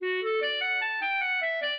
minuet2-2.wav